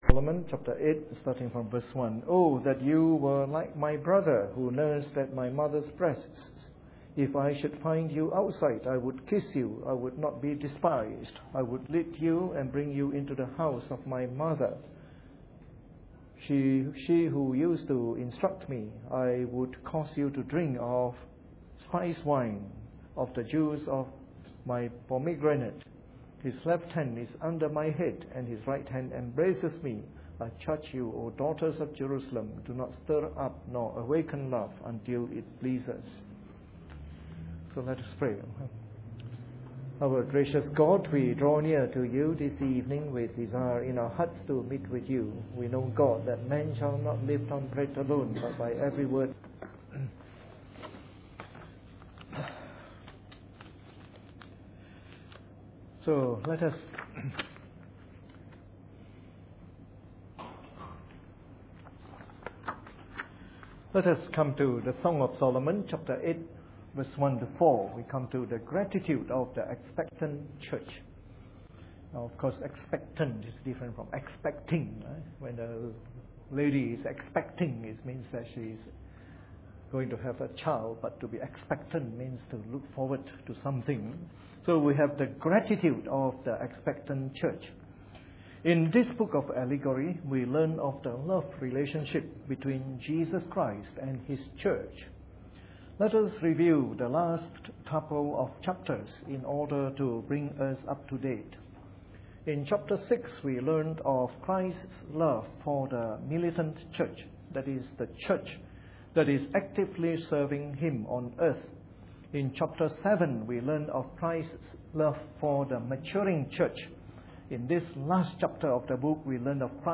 Preached on the 15th of February 2012 during the Bible Study from our series on the Song of Solomon.